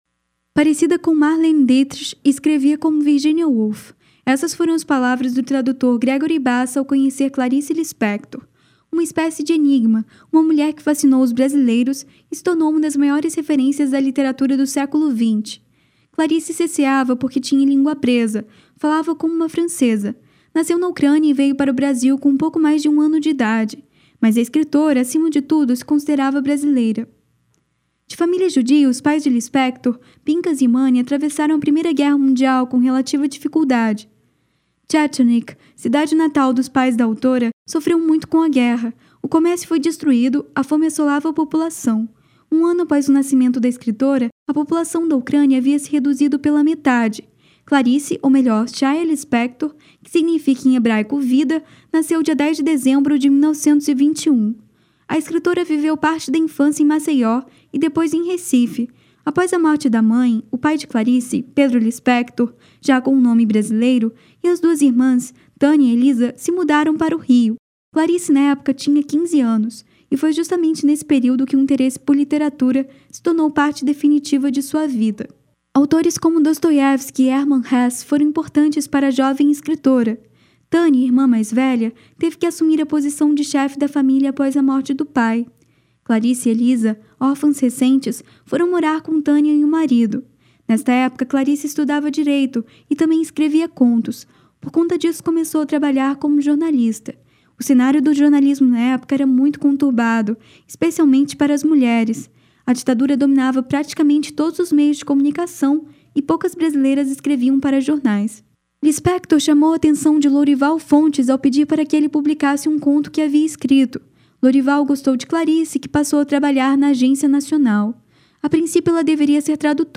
Nesta reportagem